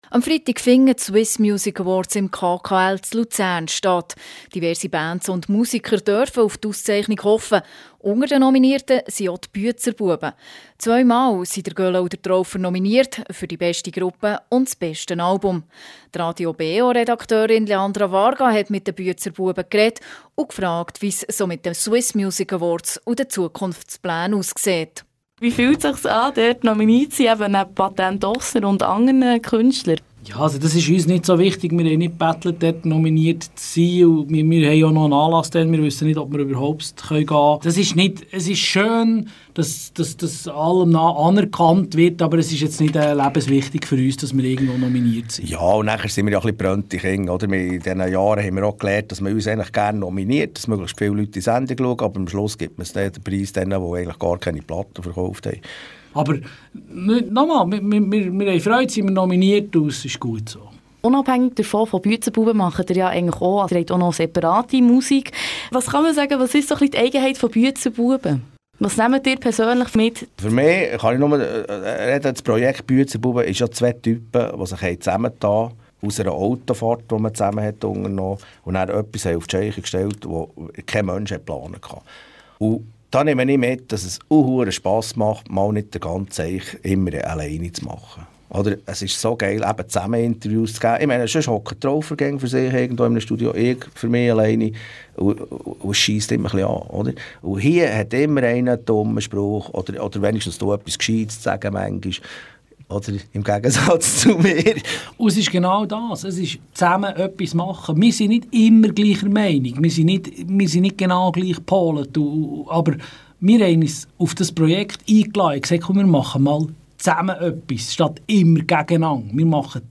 Interview mit Büetzer Buebe
Dies in einem Interview bei Radio BeO über ihre Karriere und die Musik.